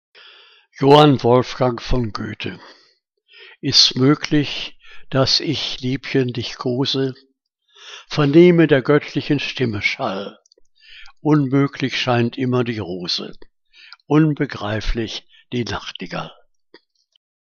Liebeslyrik deutscher Dichter und Dichterinnen - gesprochen (J. W. von Goethe)